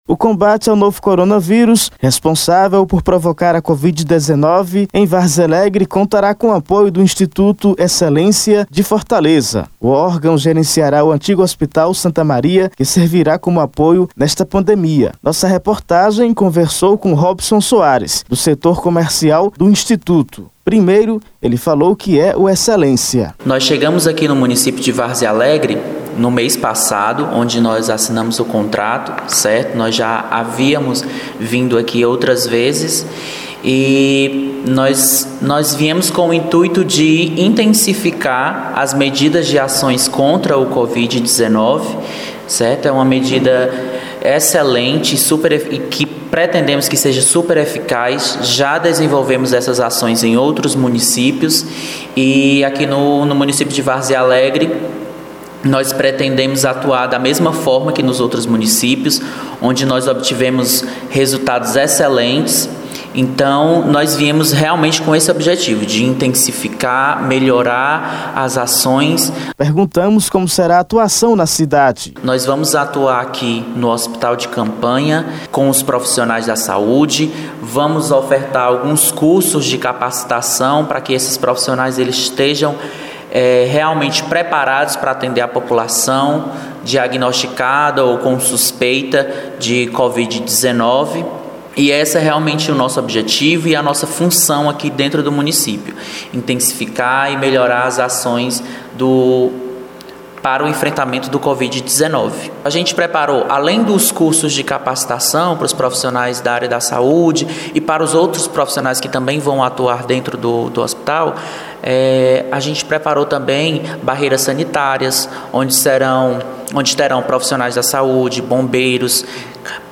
Acompanhe na reportagem: imagem da Internet